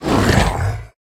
Minecraft Version Minecraft Version snapshot Latest Release | Latest Snapshot snapshot / assets / minecraft / sounds / mob / ravager / bite2.ogg Compare With Compare With Latest Release | Latest Snapshot
bite2.ogg